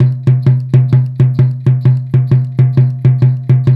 Index of /90_sSampleCDs/Spectrasonics - Supreme Beats - World Dance/BTS_Tabla_Frames/BTS_Frame Drums